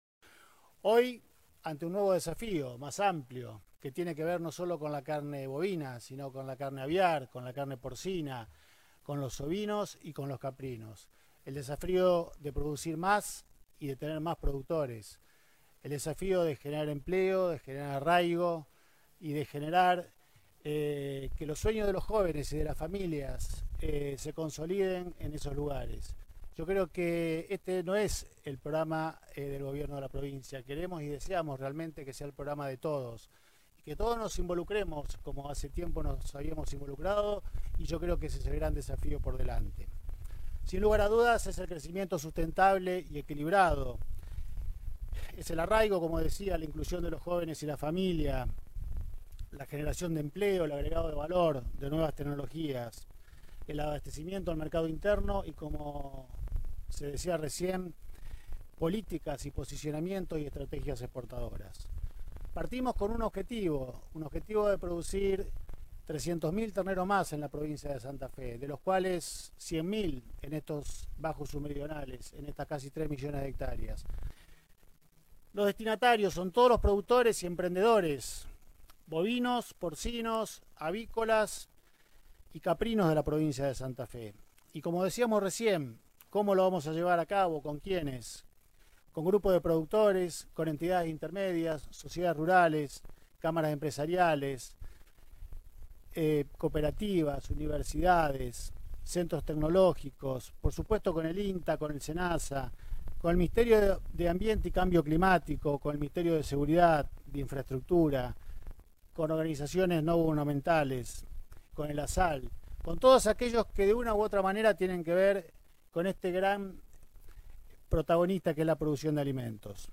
A continuación las palabras del Gobernador de Santa Fe y del Ministro de la Producción, Ciencia y Tecnología